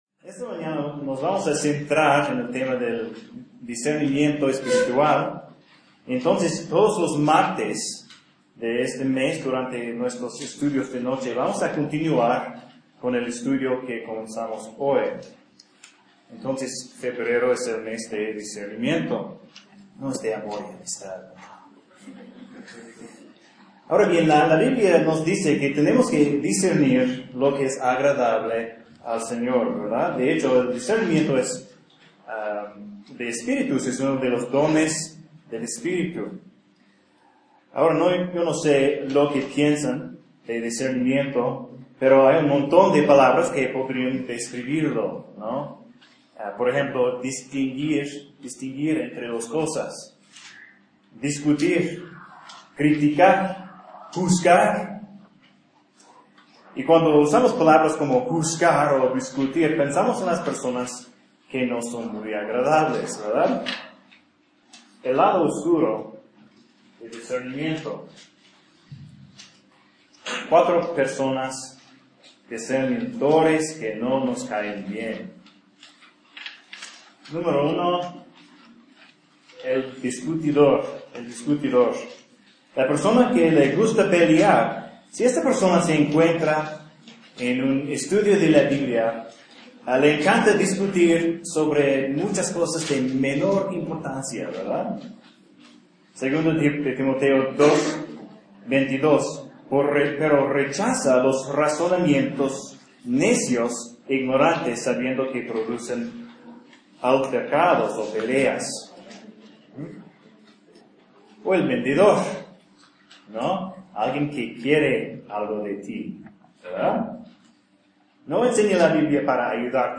Un sermón sobre discernimiento espiritual – como distinguir entre la verdad y el error, el bien y el mal.